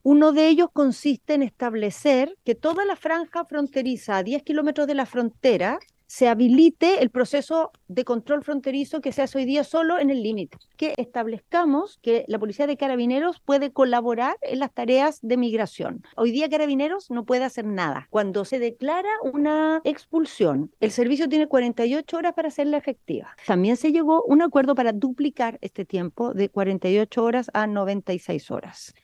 La sesión de Gobierno Interior de la Cámara recibió a la ministra del Interior, Carolina Tohá, para conocer la opinión del Ejecutivo sobre cinco proyectos que modifican la Ley 21.325 de Migración y Extranjería en diferentes materias: notificación; recursos administrativos; y reconducción de extranjeros.